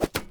arrow-impact-2.mp3